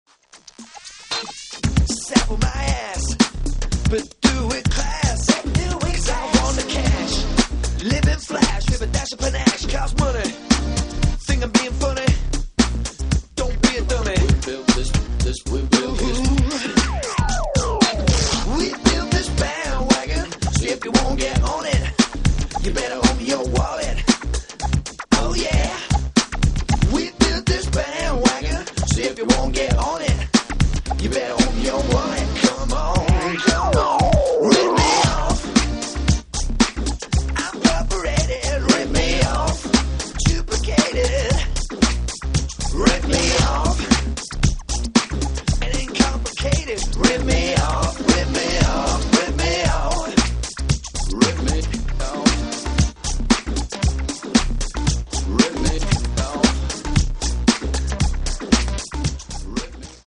Clever slo-burn electro pop house.